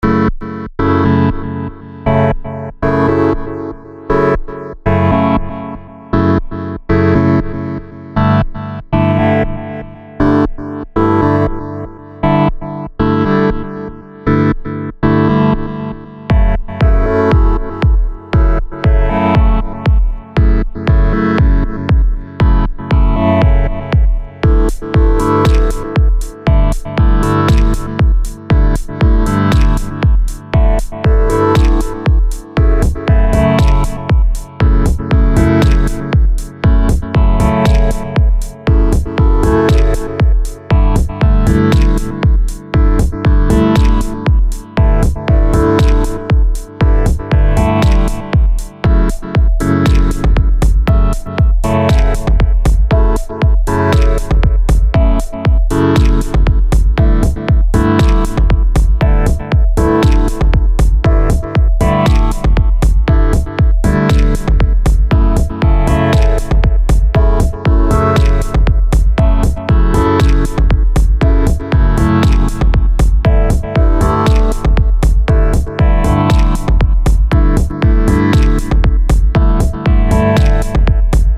loopable background theme